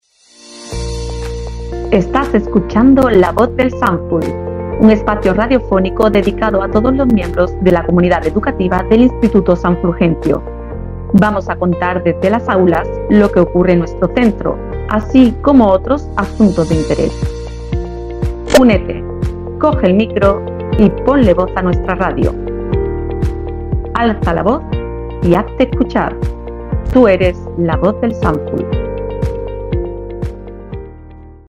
AUDIOS PARA PUBLICIDAD
– Anuncio 02 (la voz del Sanful).
Anuncio-radiosanful-02.mp3